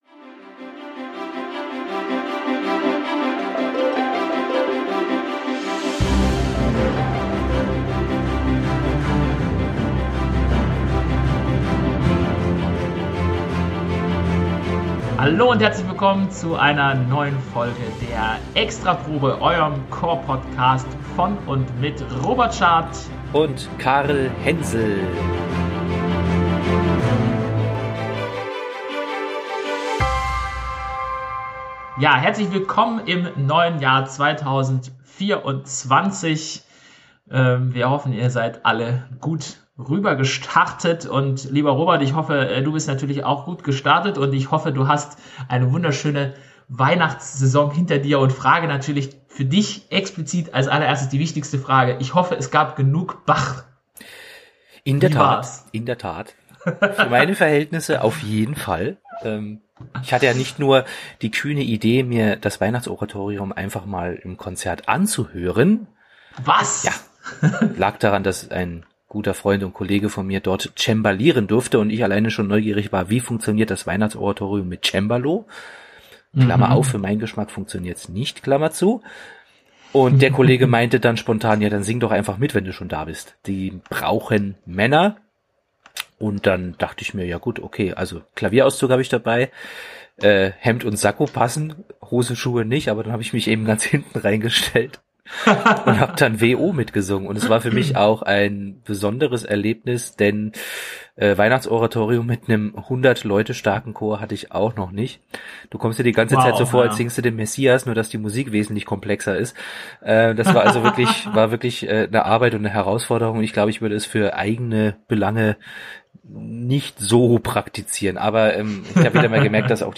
Beim nächsten Mal hört ihr uns dann hoffentlich wieder in gewohnter Qualität!